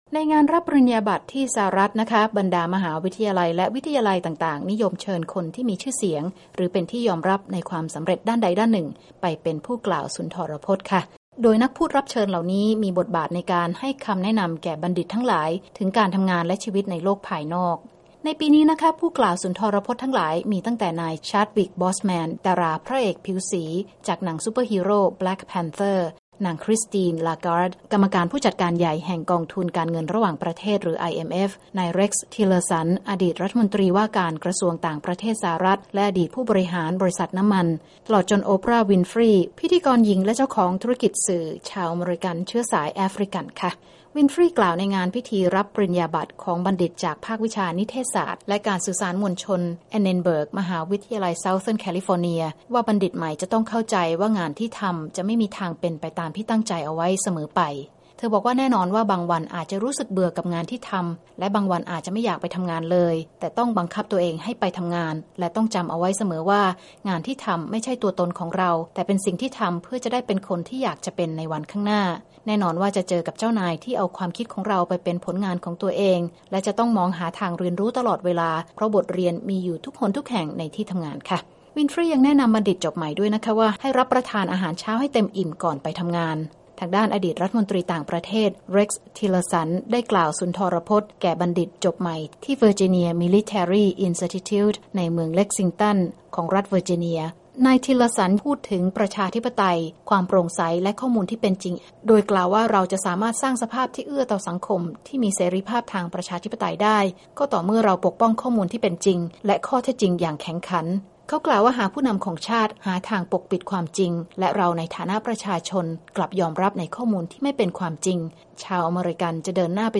ข่าวการศึกษา 27 พ.ค. 2561